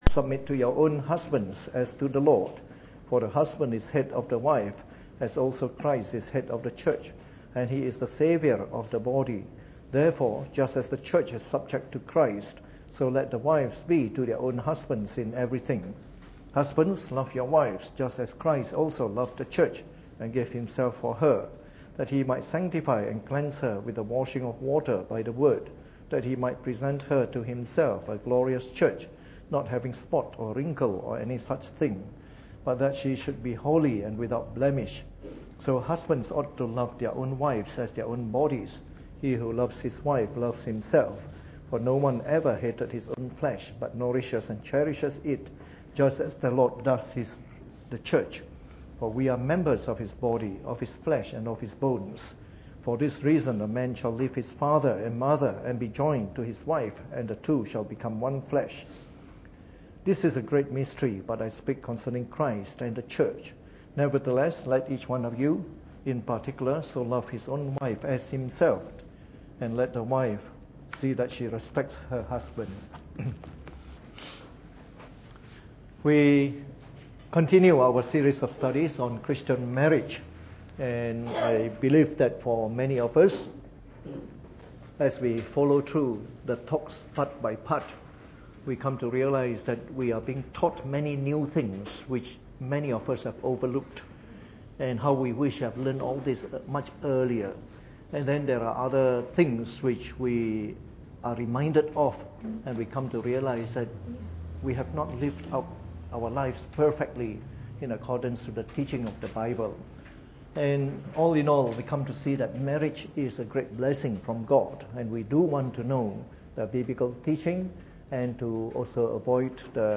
Preached on the 12th of August 2015 during the Bible Study, from our series on “Christian Marriage.”